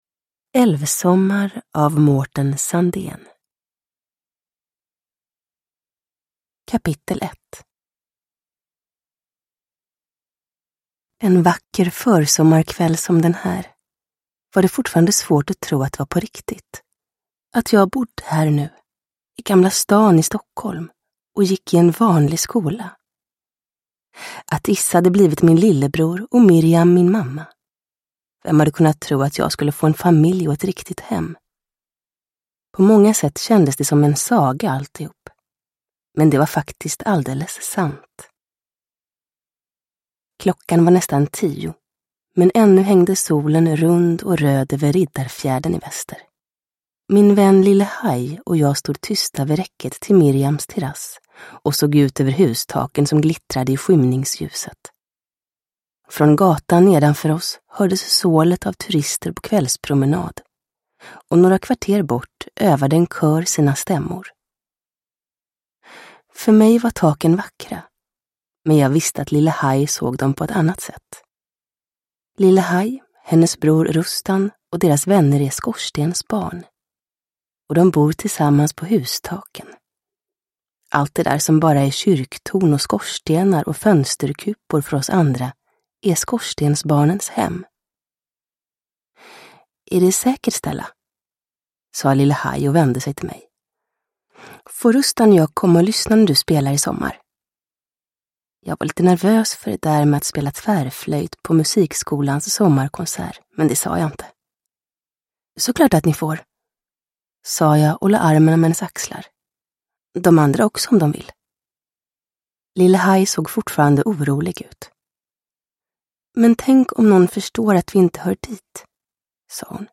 Älvsommar – Ljudbok – Laddas ner
Uppläsare: Julia Dufvenius